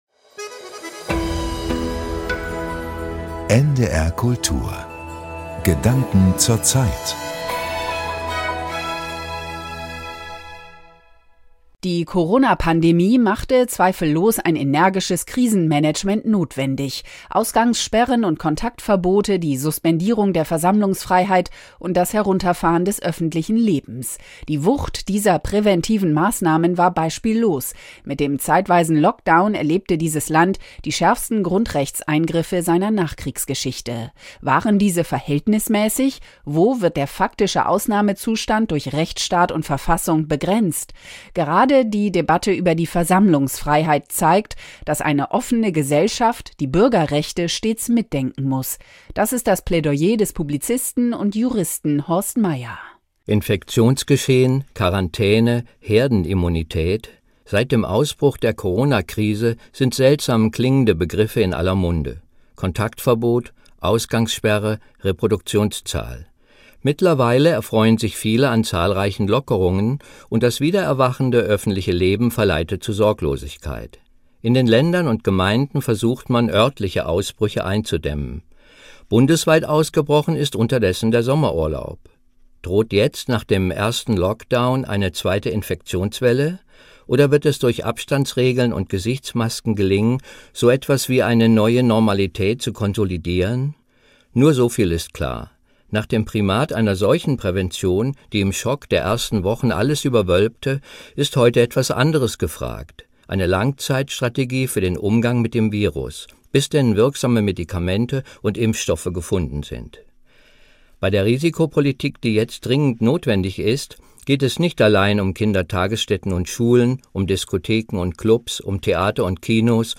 Essay Gedanken zur Zeit, NDR Kultur, 12.